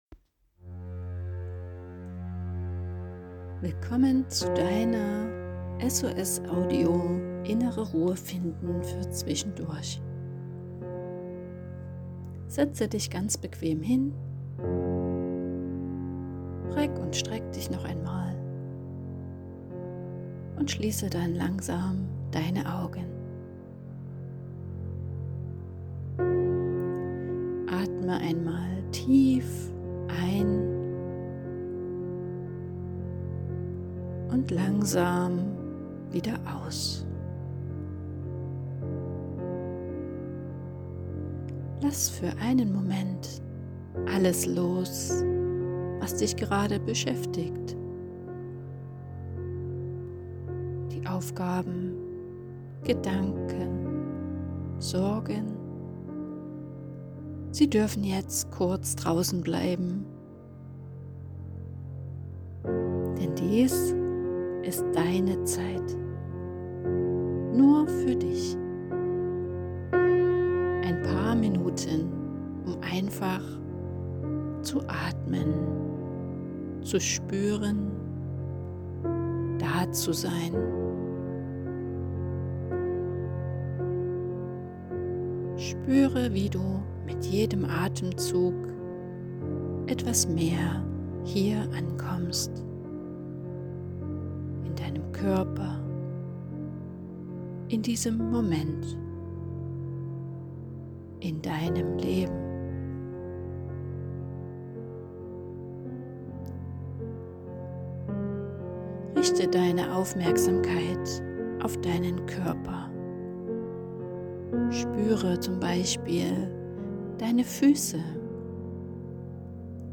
8-minütige geführte Audio-Übung mit dem Ziel, schnell aus dem Gedankenstrudel auszusteigen und wieder entspannter zu sein.*
SOS-Audio-Lange-Version-mit-Musik.mp3